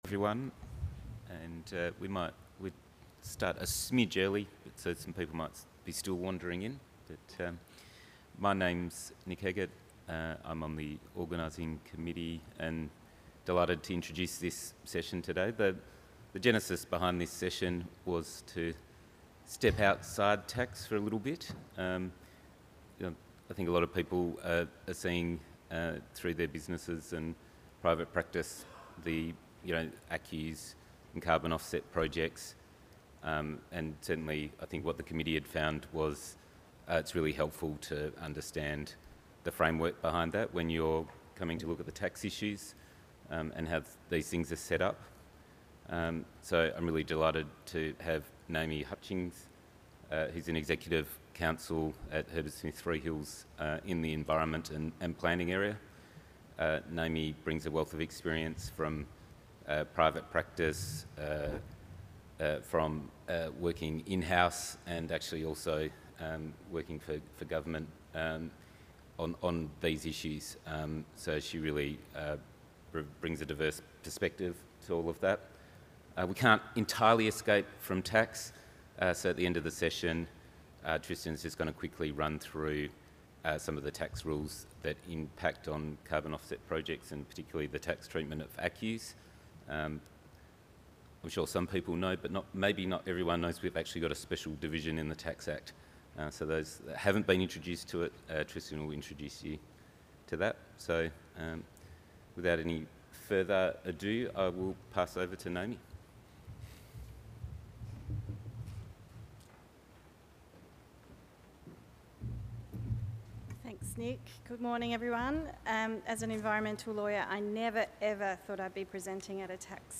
Event Name: National Resources Tax Conference
Took place at: The Westin Perth